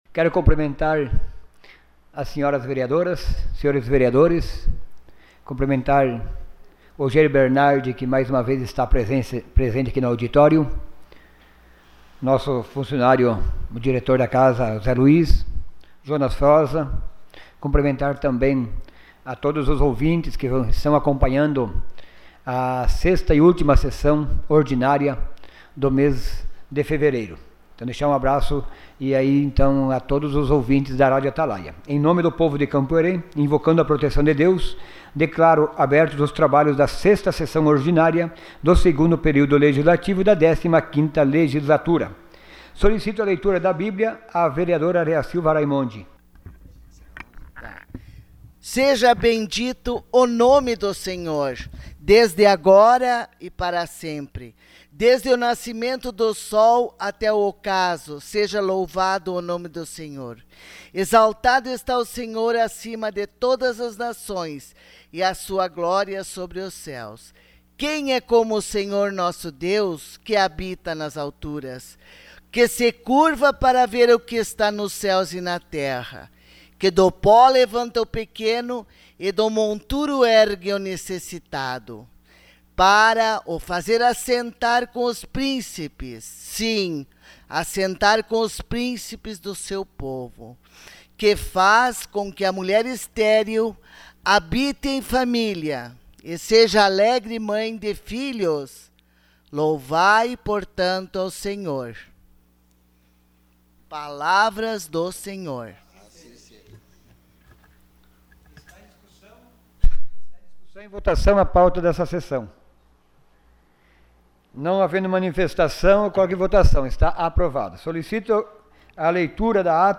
Sessão Ordinária dia 28 de fevereiro de 2018.